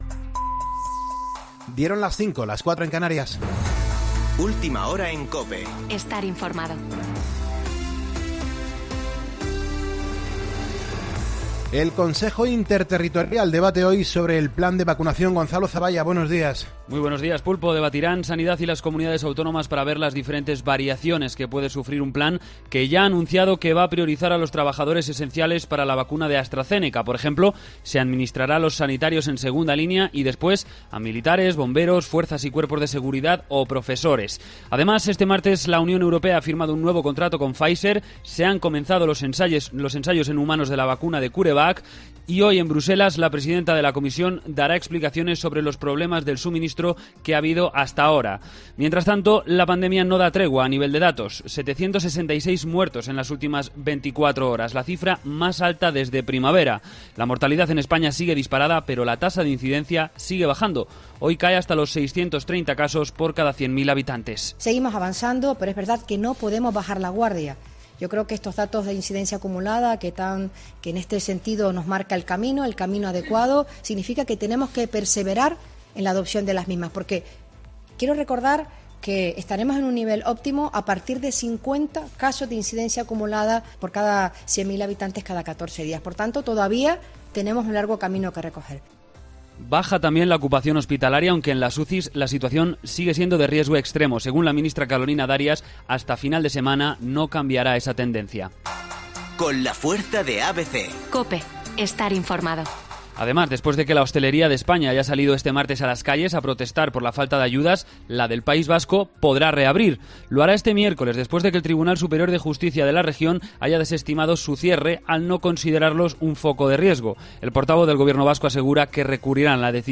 Boletín de noticias COPE del 10 de febrero de 2021 a las 05.00 horas